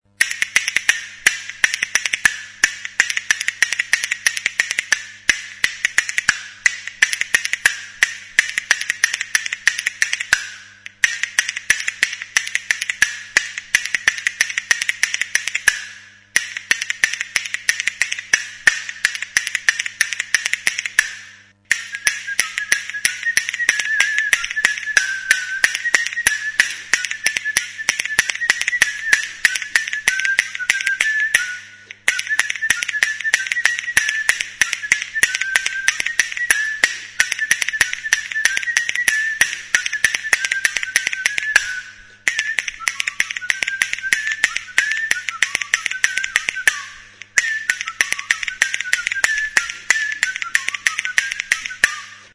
ARXALUAK; CASTAÑUELAS DE PIEDRA; HARRIZKO KASTAÑUELAK
Idiophones -> Struck -> Indirectly